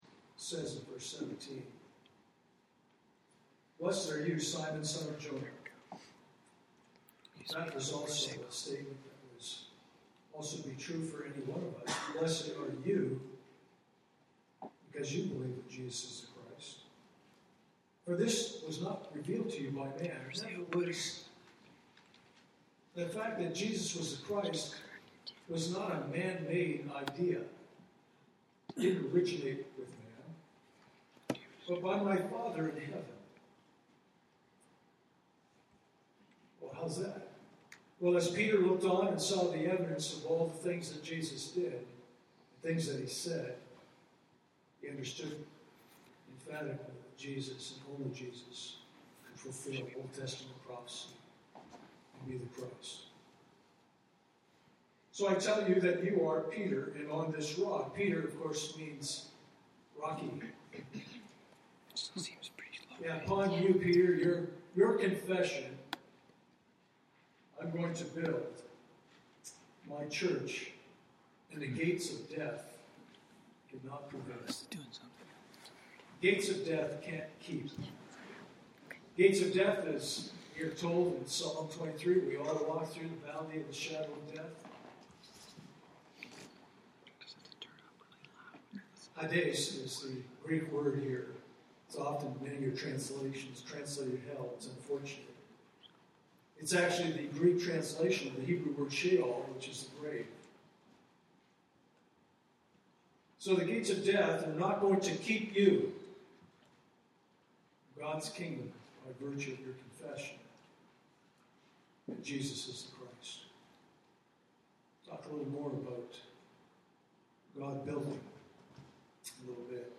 Communion Service
Communion Passage: Ezekiel 43:1-7 Service Type: Sunday Morning « Are You a Paul?